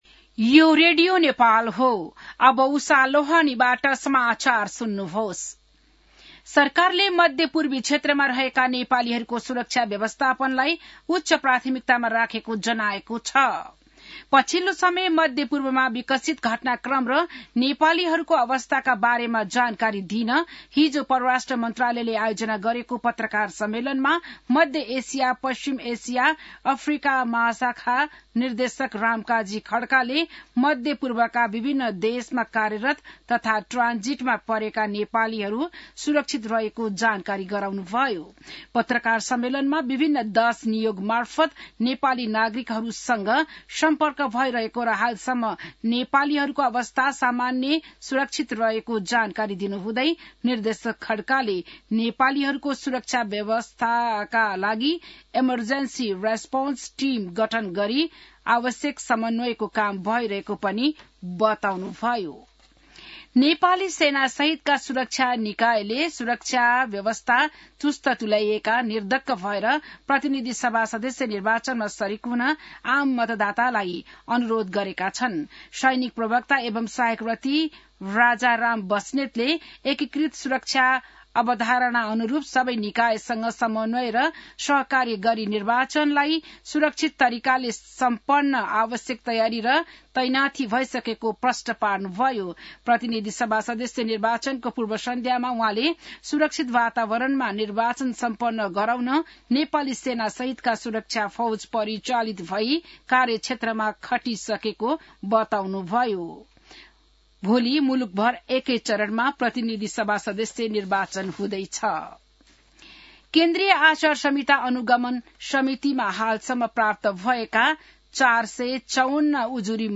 बिहान १० बजेको नेपाली समाचार : २० फागुन , २०८२